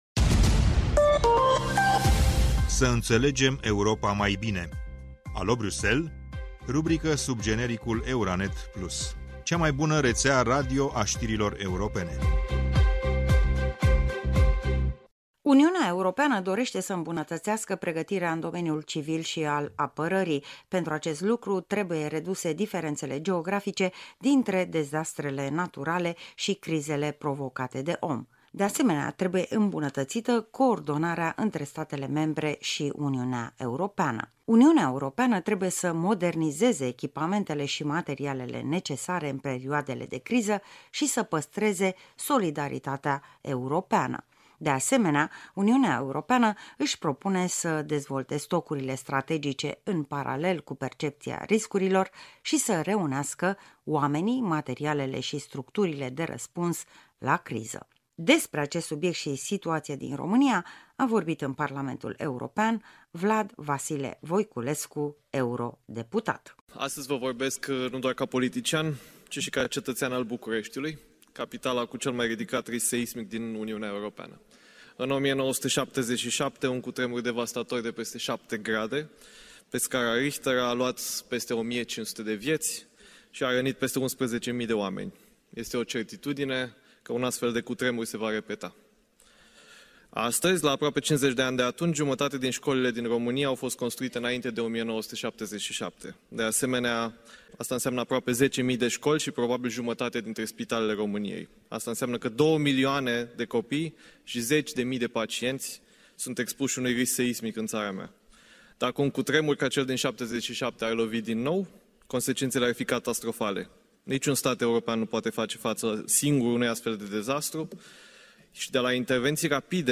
Despre acest subiect și situația din România a vorbit în Parlamentul European, Vlad Vasile-Voiculescu, eurodeputat.